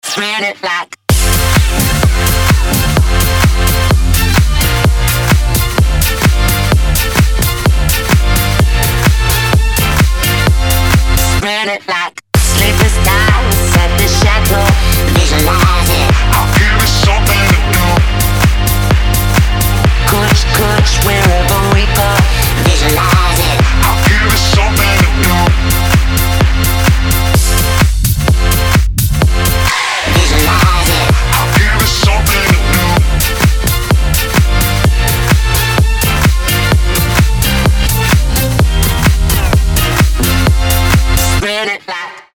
dance
Electronic